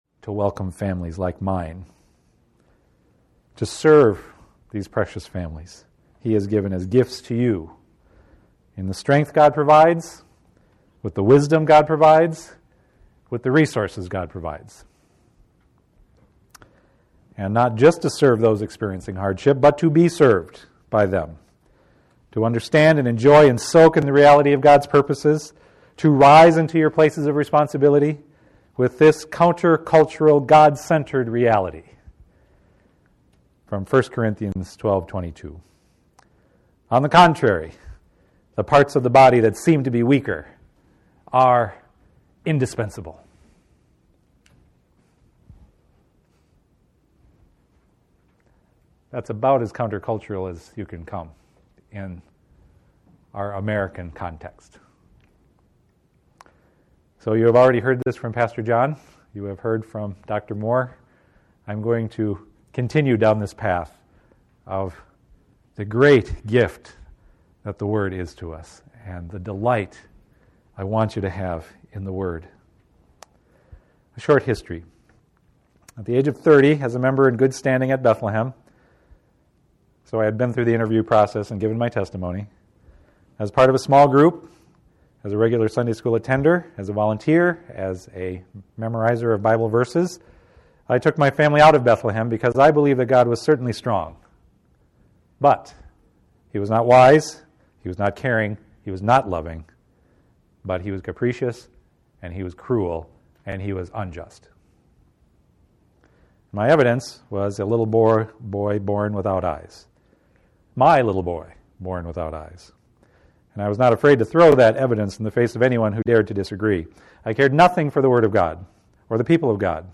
Children Desiring God Conference Seminar Audio Now Available